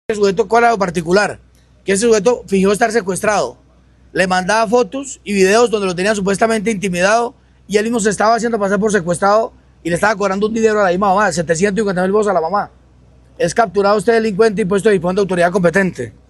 Brigadier General William Quintero Salazar Comandante Policía Metropolitana de Bucaramanga